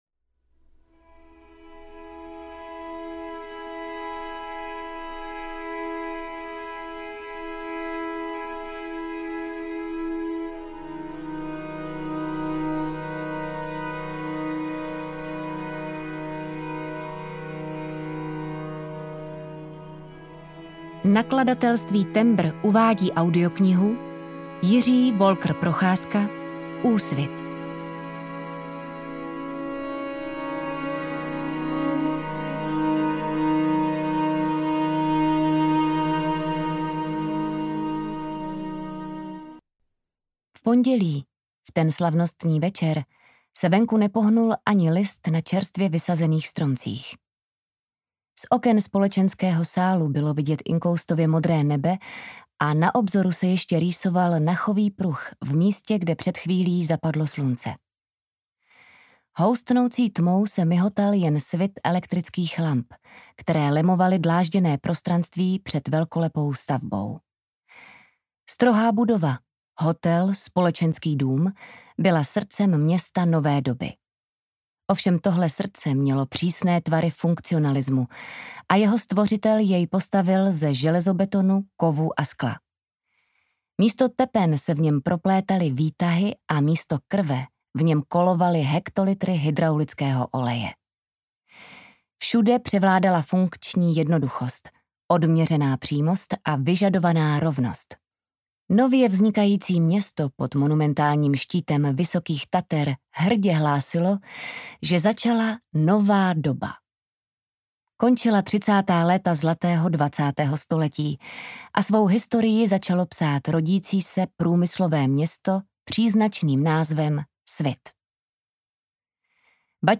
Úsvit audiokniha
Ukázka z knihy
• InterpretAnita Krausová